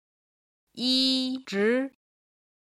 今日の振り返り！中国語発声
01-yizhi.mp3